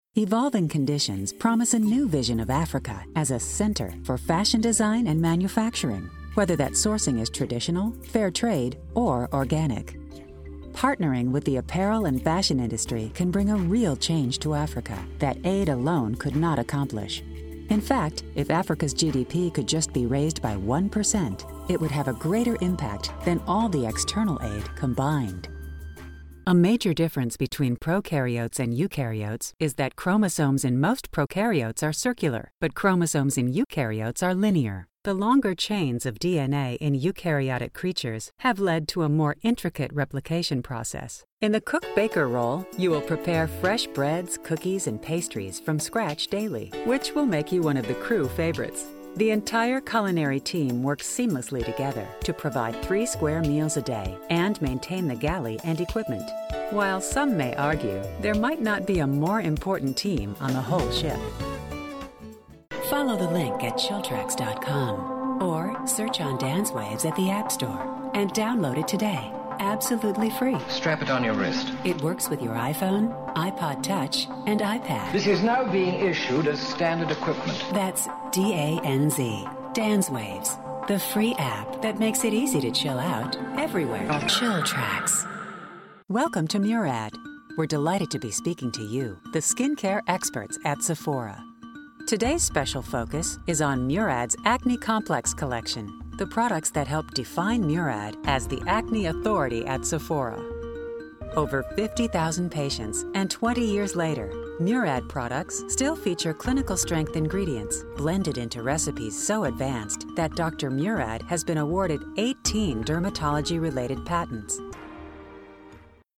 Female
English (North American)
Adult (30-50), Older Sound (50+)
A smooth, alluring, corporate, professional voice.
Narration